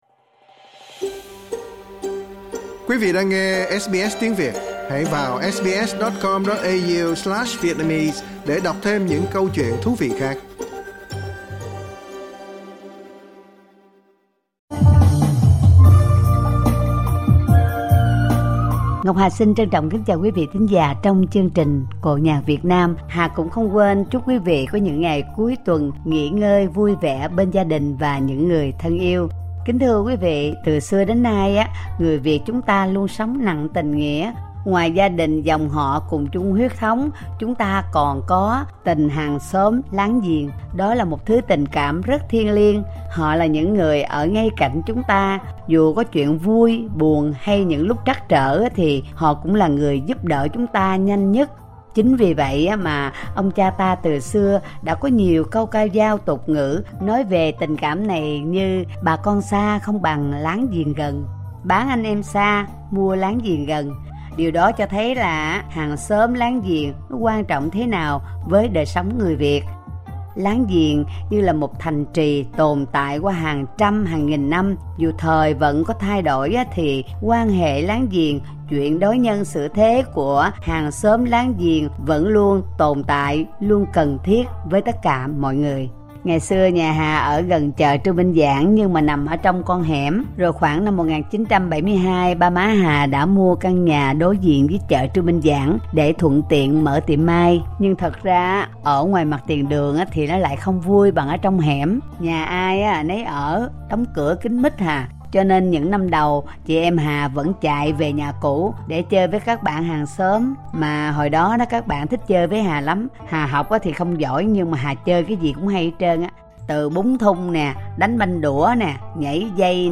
ca cảnh
thể điệu Bình bán Chấn 10 câu